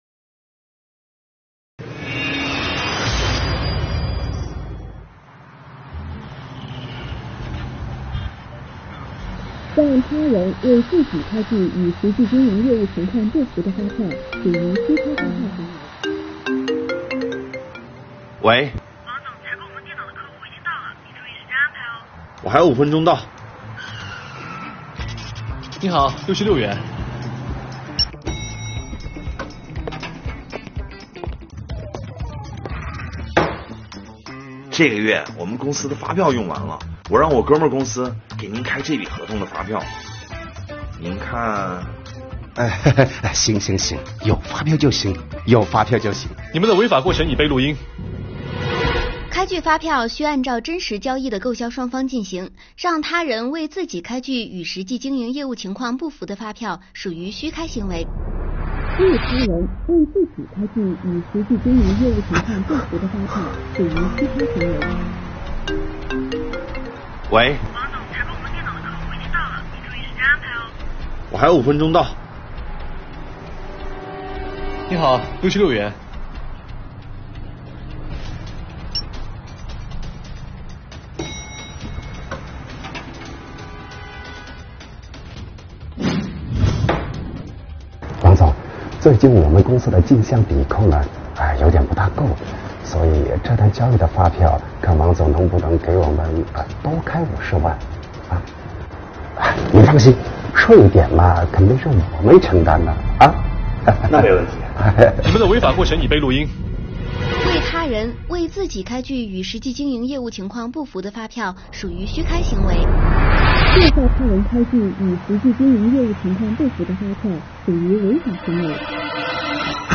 短片用多组重复情节和镜头讲述老板面对虚开发票这一问题的处理方式，情节多次反转并逐渐走向明朗。作品节奏和音乐把握恰当，运镜流畅舒适，引发受众的观看兴趣。